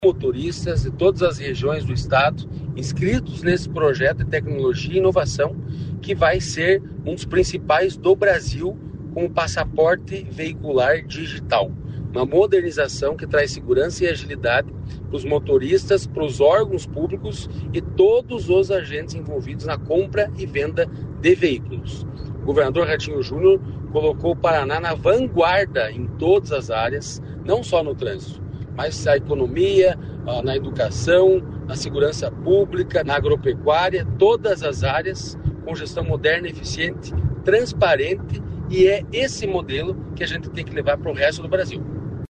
Sonora do presidente do Detran, Santin Roveda, sobre o projeto-piloto do Passaporte Veicular Digital